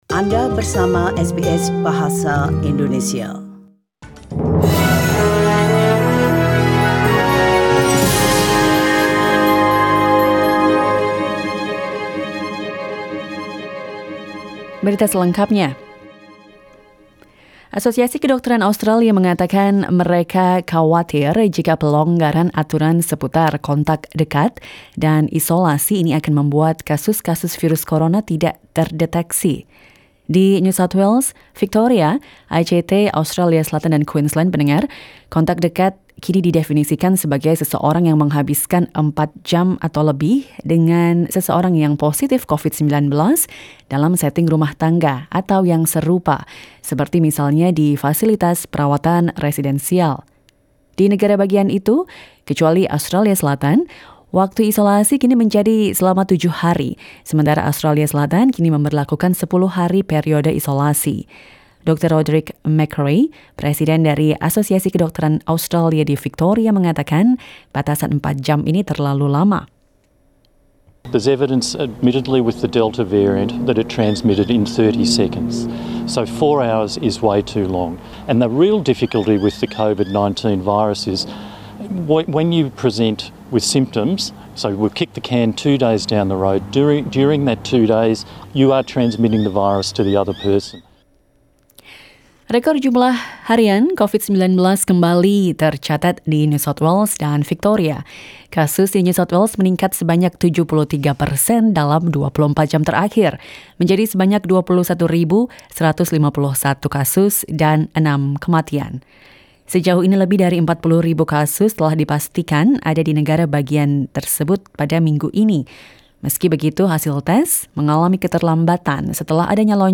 SBS Radio news in Bahasa Indonesia - 31 December 2021
Warta Berita Radio SBS Program Bahasa Indonesia.